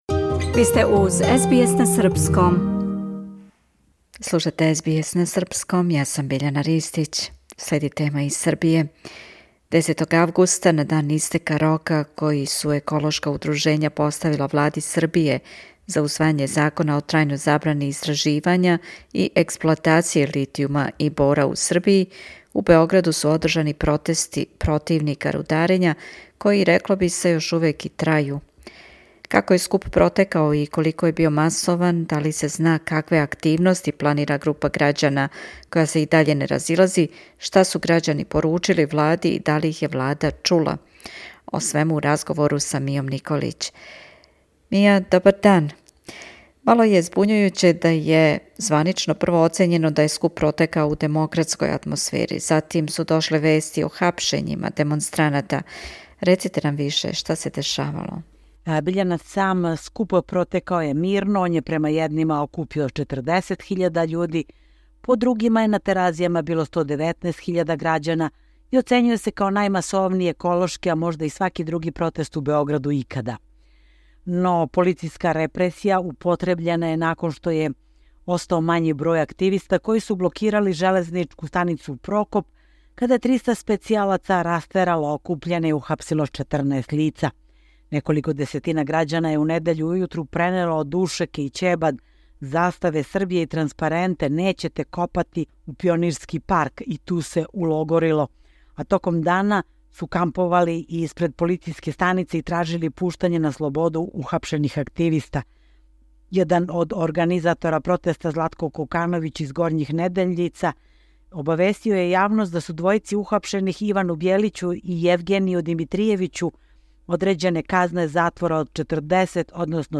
Како је скуп протекао и колико је био масован, да ли се зна какве активности планира група грађана која се и даље не разилази, шта су грађани поручили Влади и да ли их је Влада чула? О свему у разговору